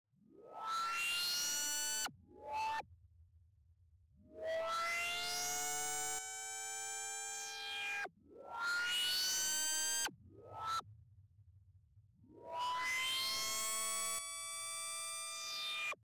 奇怪的噪音效果 " 噪音效果5c
描述：第五种噪音效果的第三种变体 请给我一个评论，我接下来要发出声音，谢谢:)。
标签： 未来的 怪异的 奇怪 实际上 科幻 噪音
声道立体声